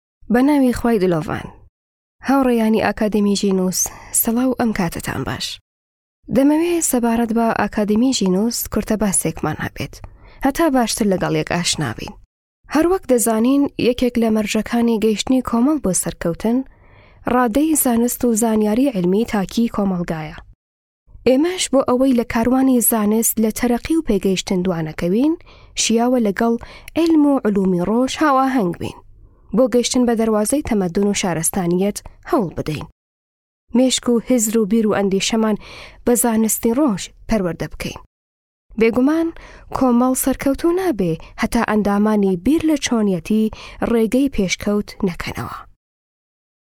Female
Adult
Commercial